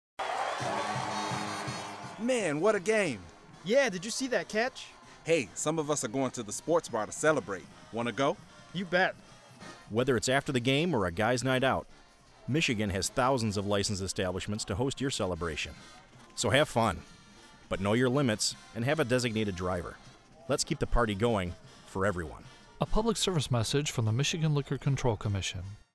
Sports themed public service announcement